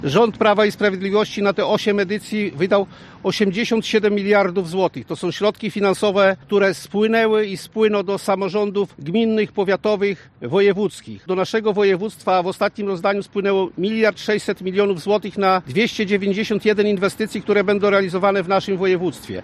Rząd przeznaczył na cele powiatu ogromną sumę podsumował poseł Prawa i Sprawiedliwości, Kazimierz Gwiazdowski: